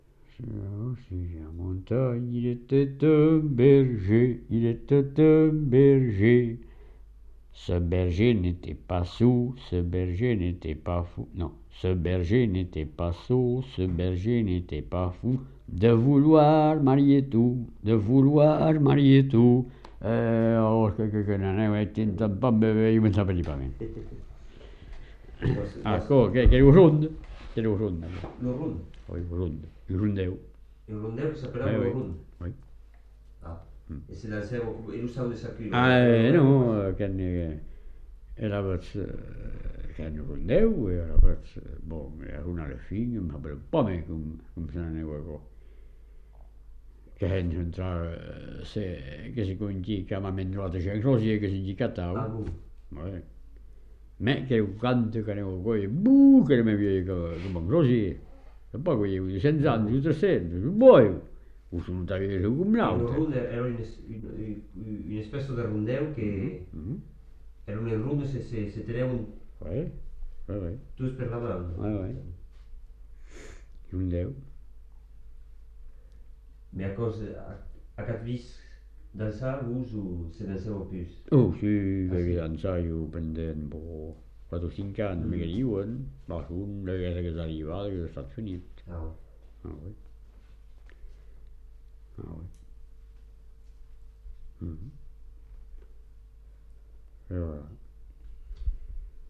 Lieu : Lévignacq
Genre : chant
Effectif : 1
Type de voix : voix d'homme
Production du son : chanté
Danse : ronde